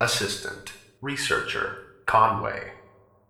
scientist1.ogg